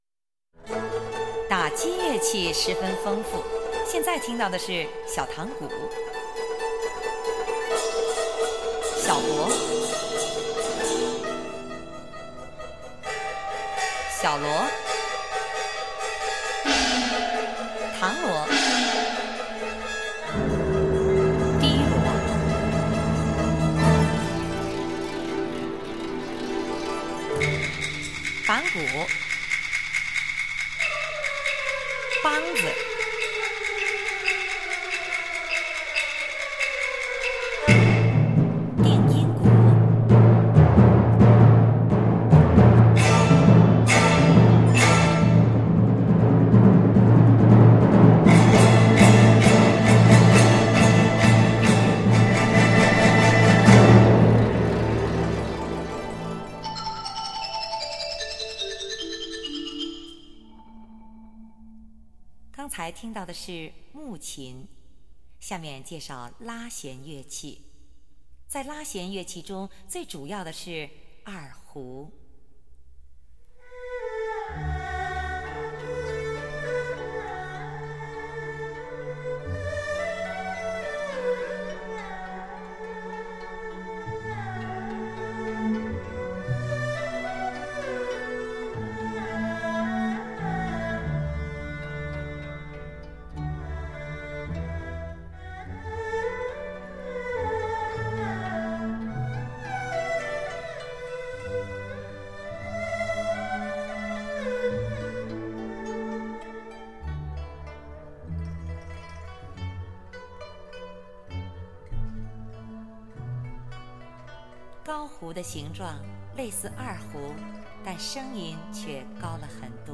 此段辑选了乐曲中介绍敲击和拉弦乐的两段
其乐器定位明显，无需按图索骥已能听出各声部位置。